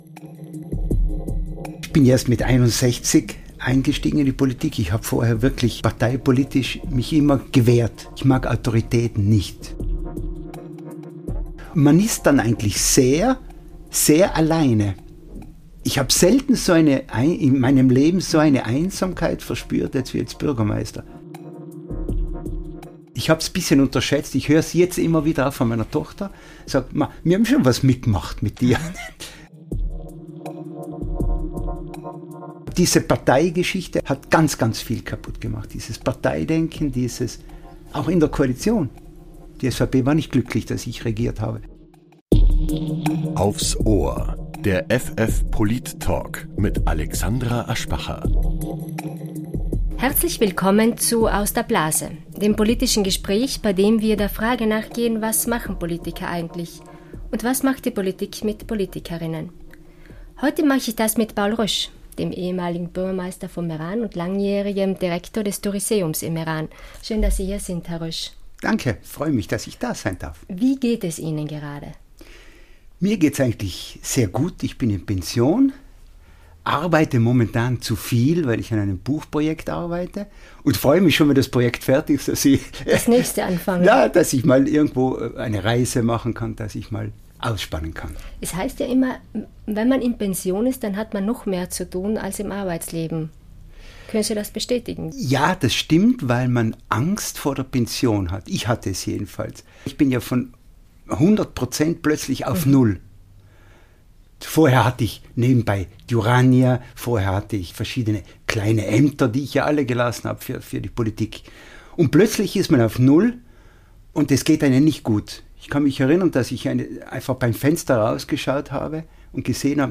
Der Politik-Talk